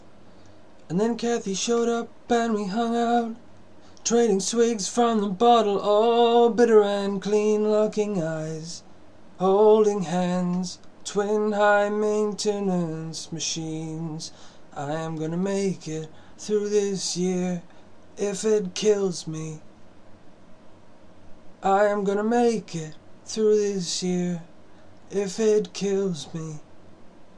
just to test my mic and so on.